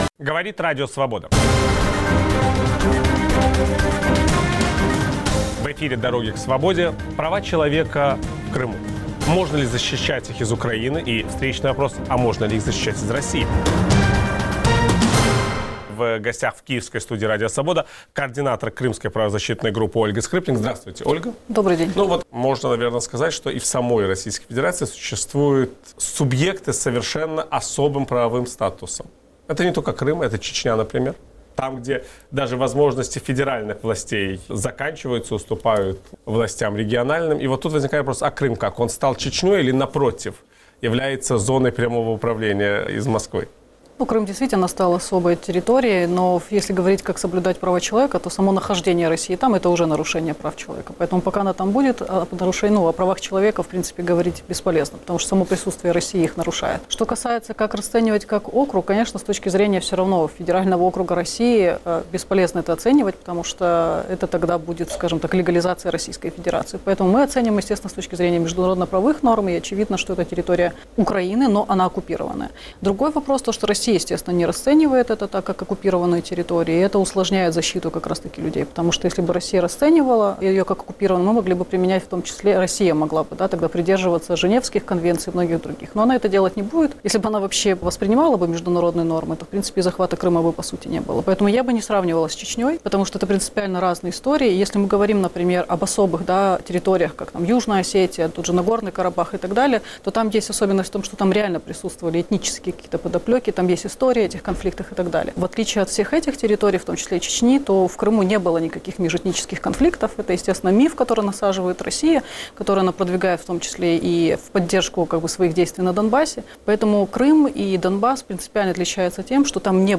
Могут ли украинские правозащитники содействовать защите прав человека в Крыму? Виталий Портников беседует